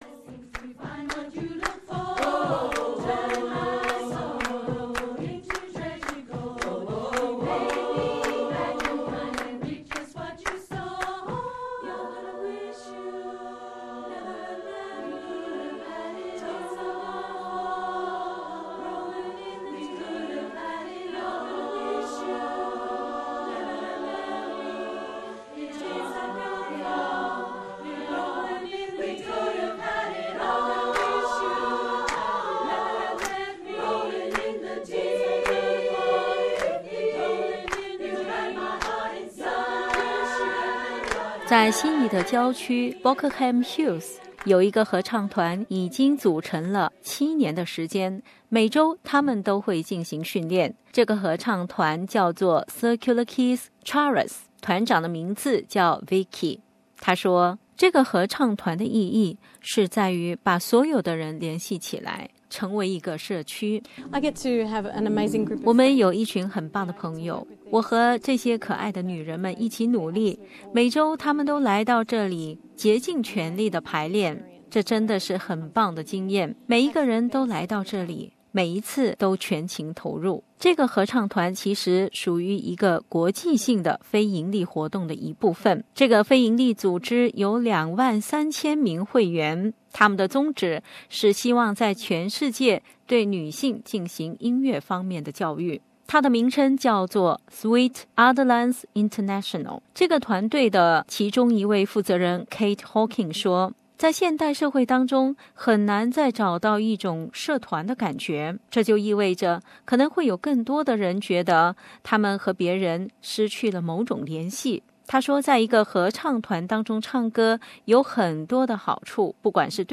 The choir in action (SBS) Source: The choir in action (SBS)